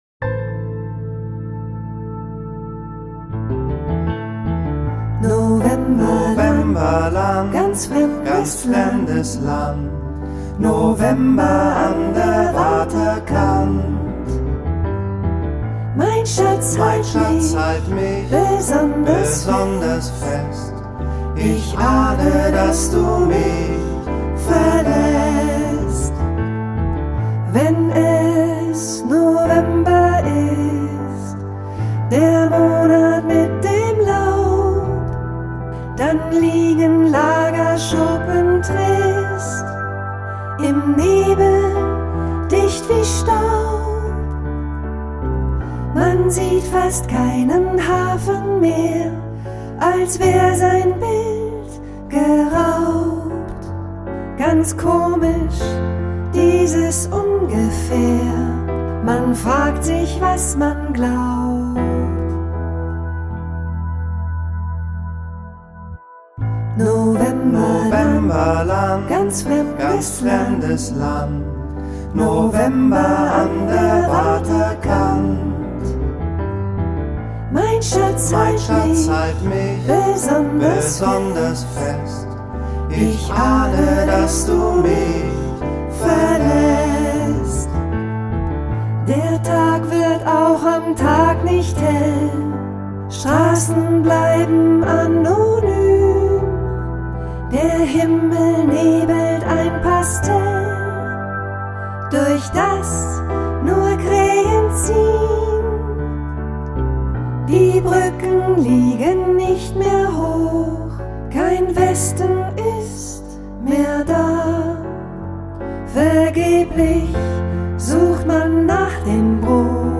Zwei Songs
Beide sehr melancholisch…